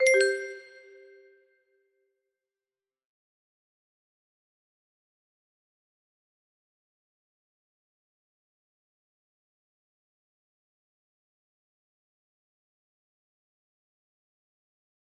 ClockIn music box melody